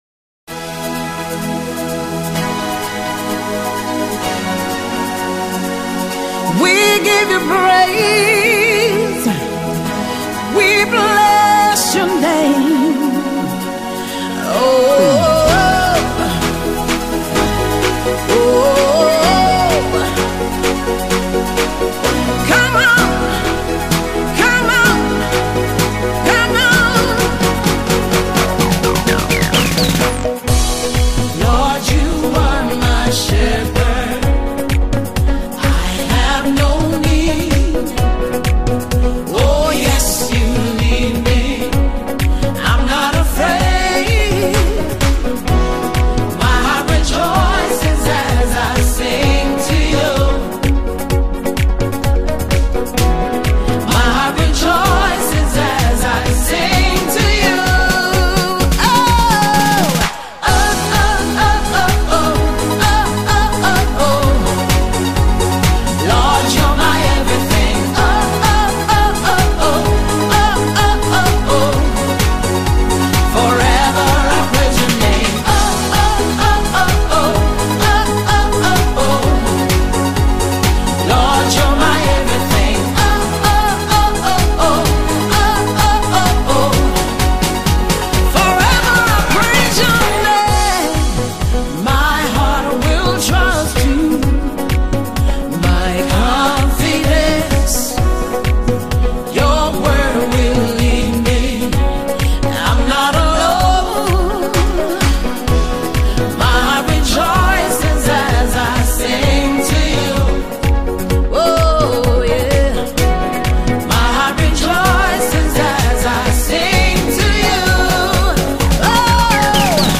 Multi award-winning Nigerian Gospel music singer
Worship Song